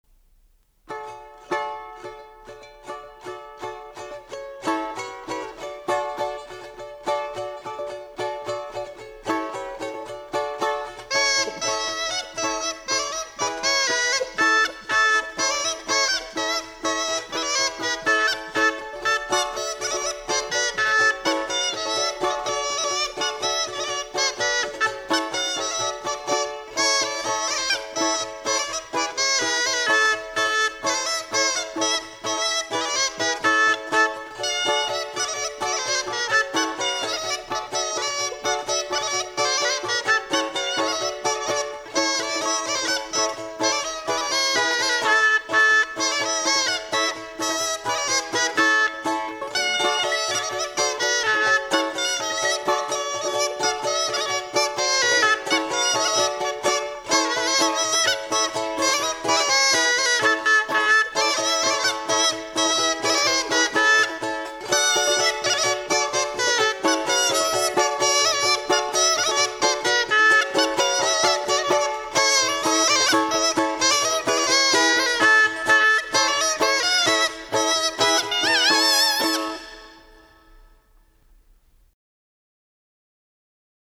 Голоса уходящего века (Курское село Илёк) Полька-птичка (балалайки, рожок, инструментальная версия)
28_Наигрыш.mp3